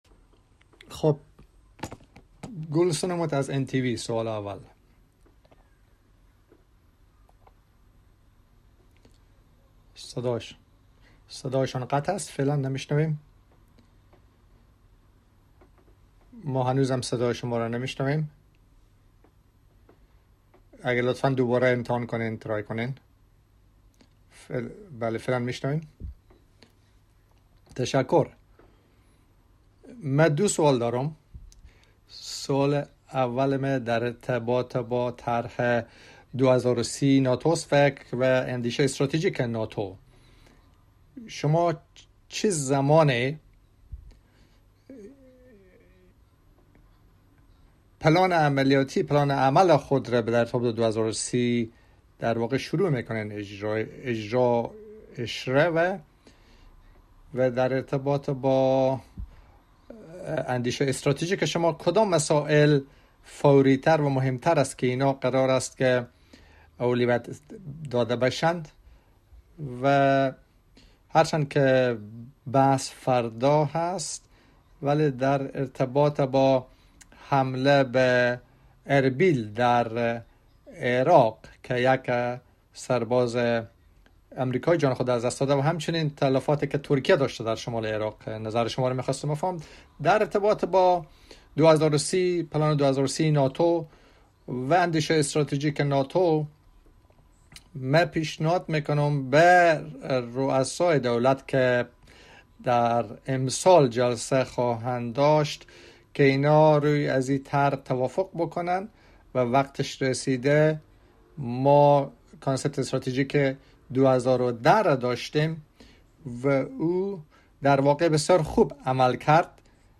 Online press conference
by NATO Secretary General Jens Stoltenberg following the first day of the meetings of NATO Defence Ministers